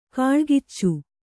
♪ kāḷgiccu